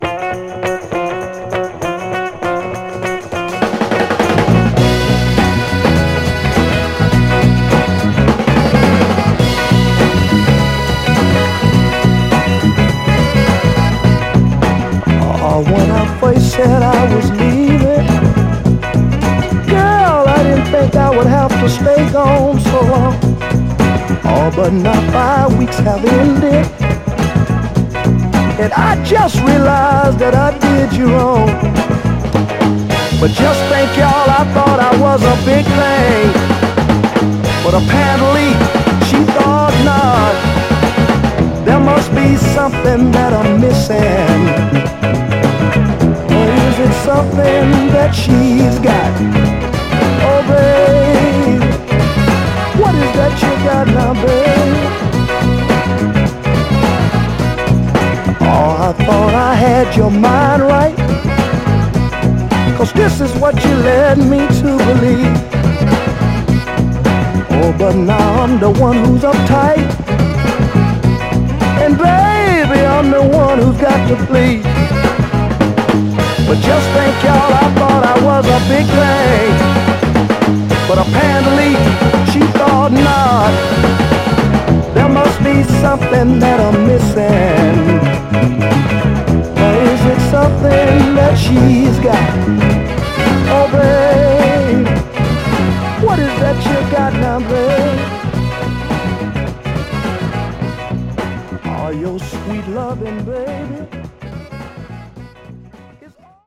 底抜けに明るい演奏＋メロディとねちっこいバリトン・ヴォーカルがとにかくクセになりますね。
※試聴音源は実際にお送りする商品から録音したものです※